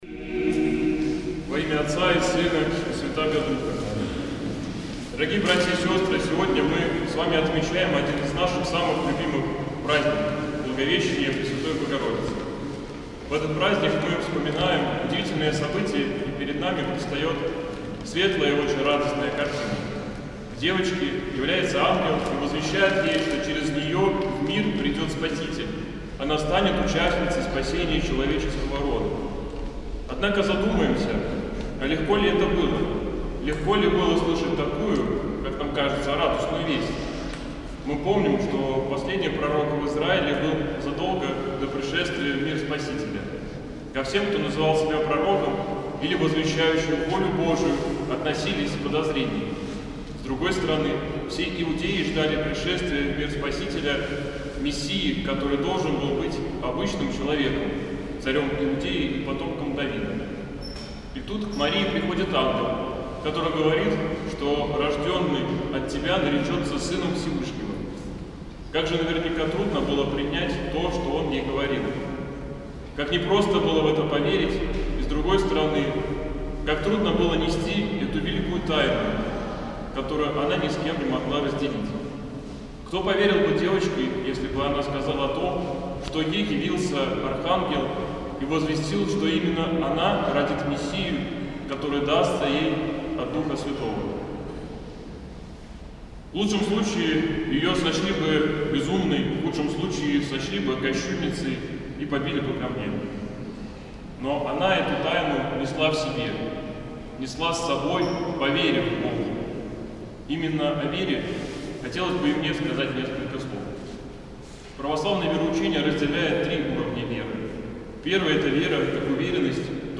Проповедь в праздник Благовещения Пресвятой Богородицы